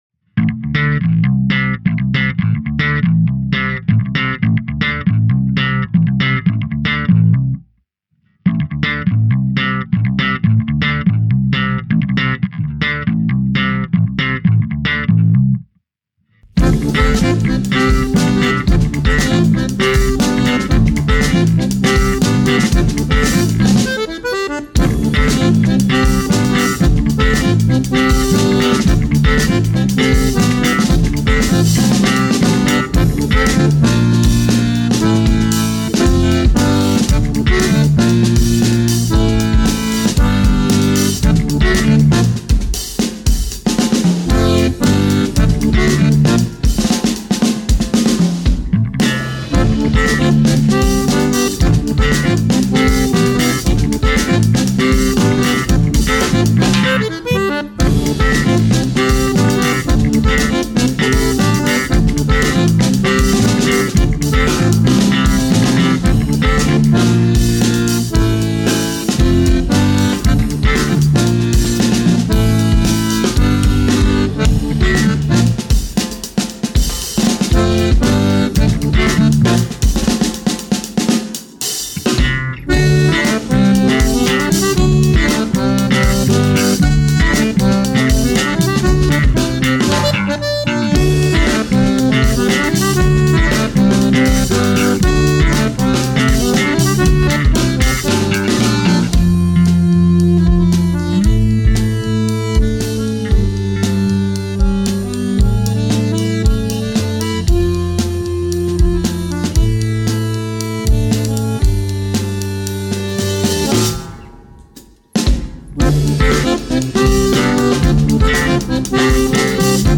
Garage Folk  aus Portugal